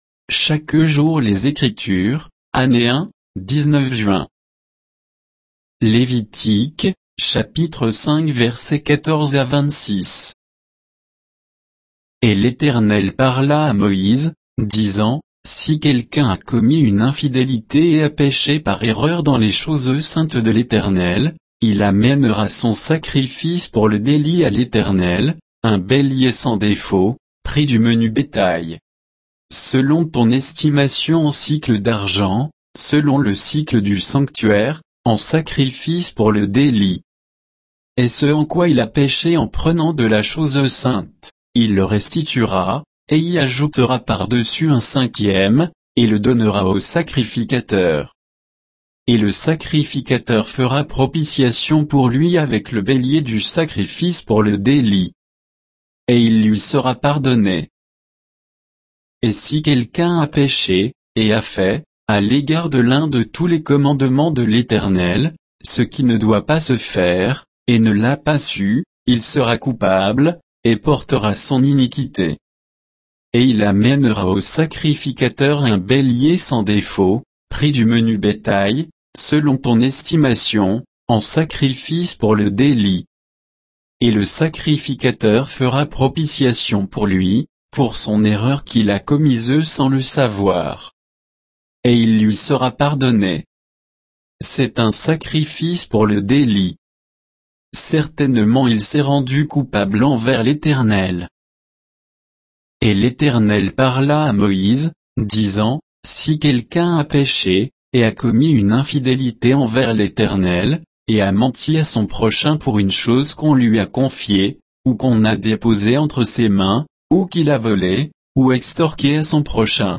Méditation quoditienne de Chaque jour les Écritures sur Lévitique 5, 14 à 26